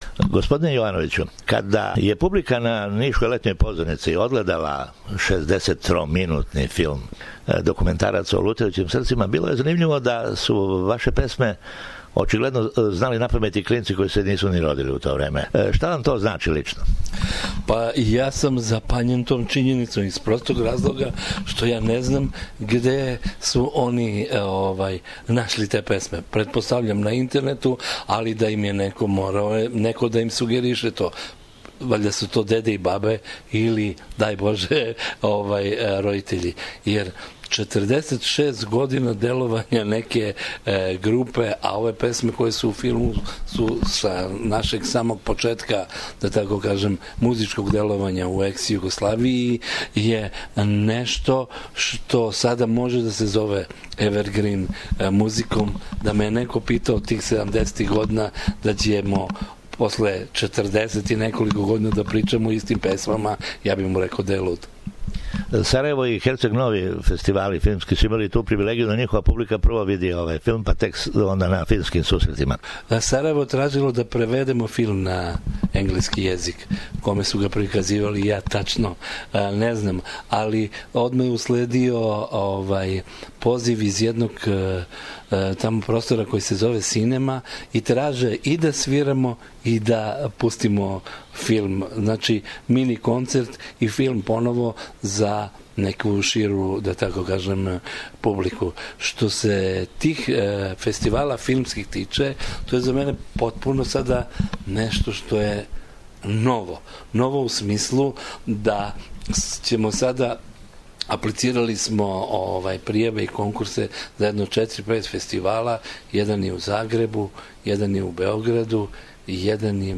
интервју са једним од чланова ове легендарне групе